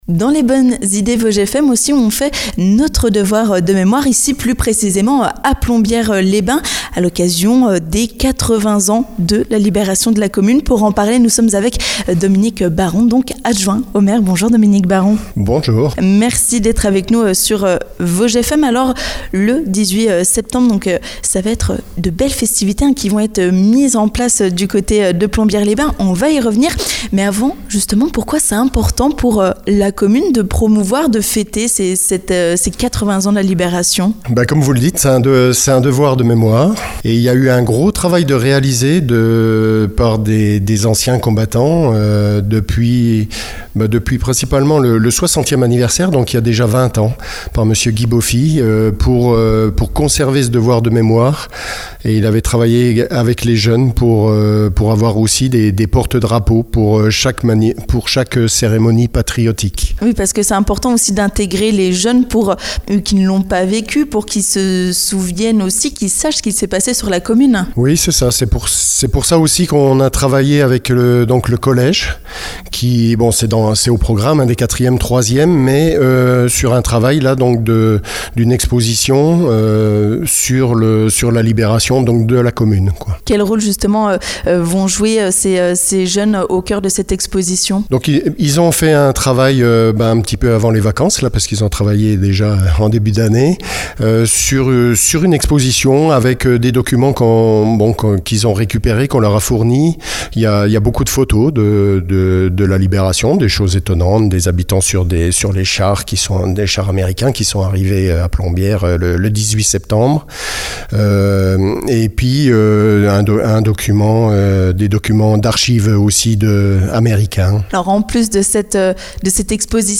Dominique Baron, adjoint à la municipalité de Plombières-les-Bains, nous en dit plus dans les Bonnes Idées Vosges FM !